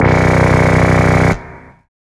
SYNTHBASS3-R.wav